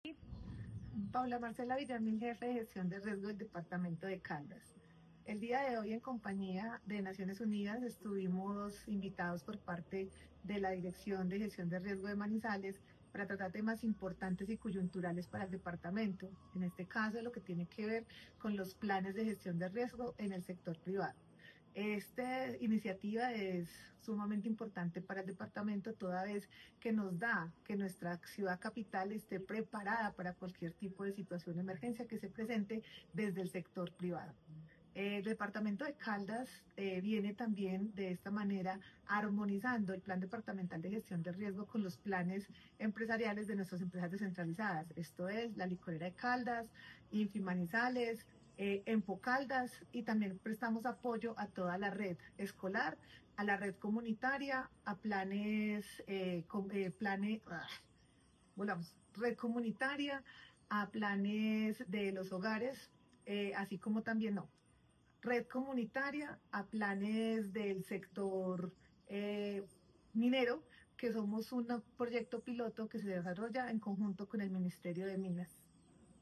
Paula Marcela Villamil, jefe de Gestión del Riesgo de Caldas